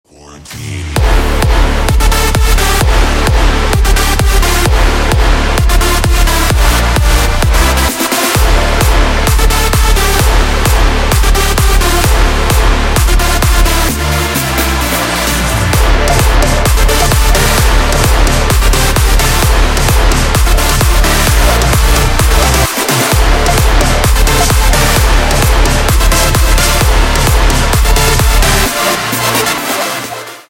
Клубные Рингтоны » # Рингтоны Без Слов
Рингтоны Электроника